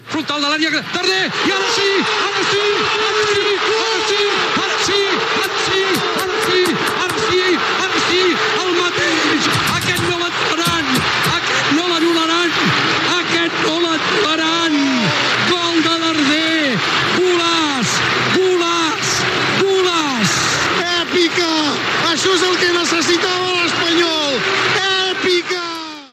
Narració del gol de Darder del RCD Espanyol en el partit de lliga contra el Rayo Vallecano que va acabar amb el resultat de 2 a 1
Esportiu